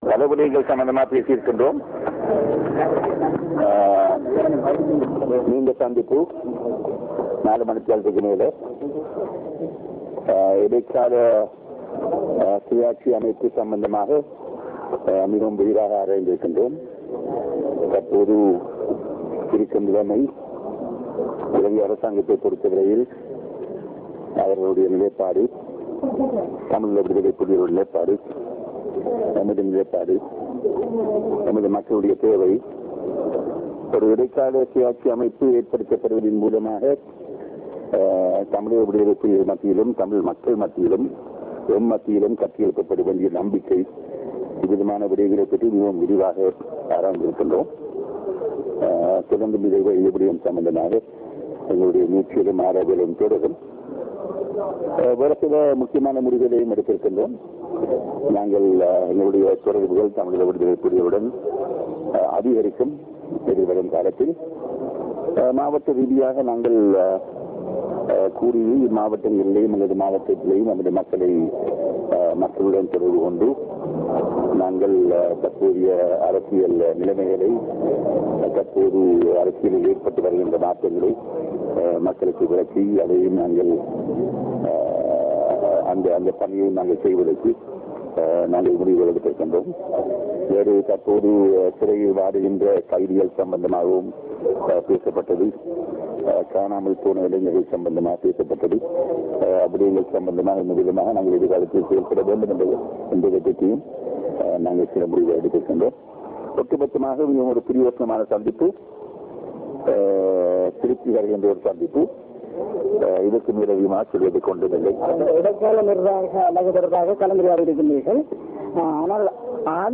Real Audio Icon TNA Legislators speaking to Press (Tamil)